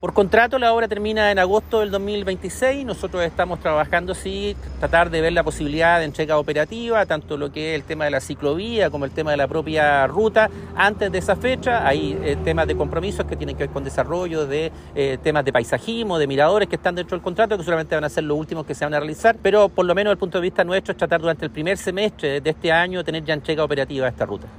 Esta obra, que incluye ciclovías, se está ejecutando en tres kilómetros del camino y el próximo mes comenzará el asfaltado, informó el seremi de Obras Públicas, Hugo Cautivo.